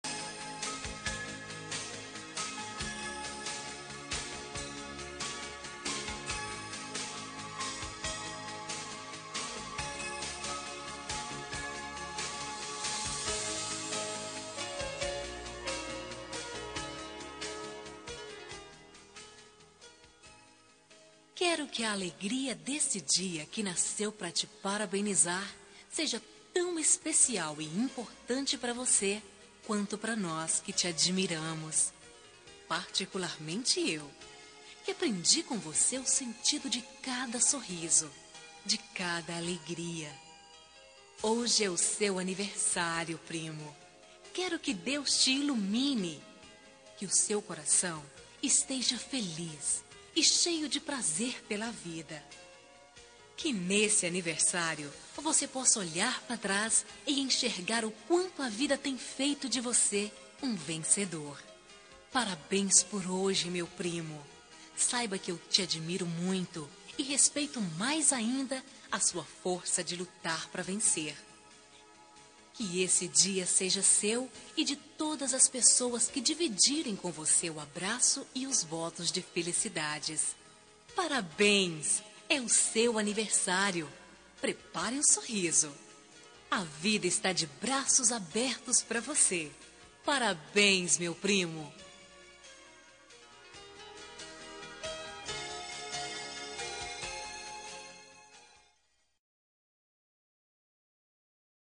Aniversário de Primo – Voz Masculina – Cód: 042827